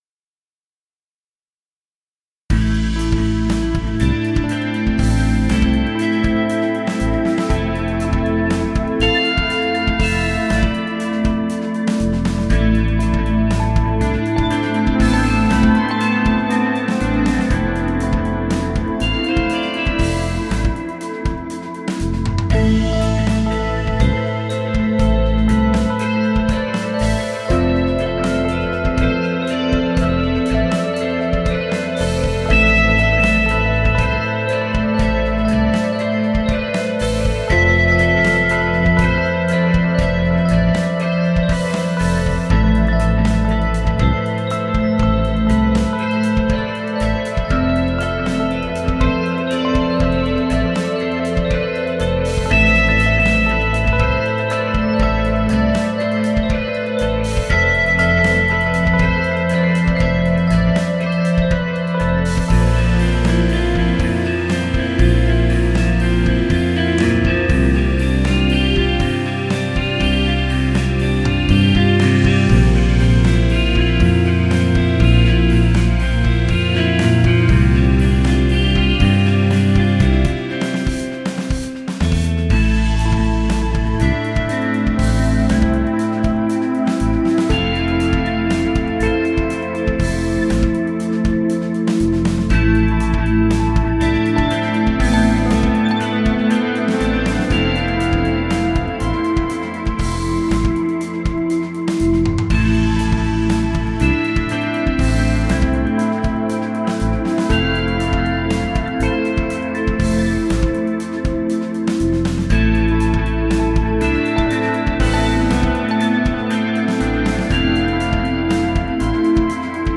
Triple Spiral Audio Endless Guitar 是一个氛围吉他音色库，由一把7弦的 Strandberg Prog Boden 吉他的录音制作而成，通过 Neural DSP Quad Cortex 数字音箱重新放大，并通过各种效果重新设计。
声音的范围从美丽干净的音调到清脆和低保真的声音不等。还包括一系列氛围和铺底。
- FX - 混响，延迟，合唱，相位器，饱和度，Skreamer